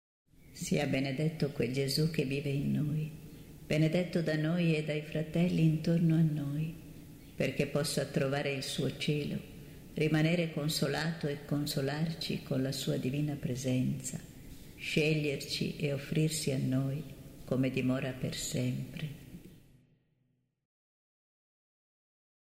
preghiera mp3 – Il mio nome fra le Stelle canto mp3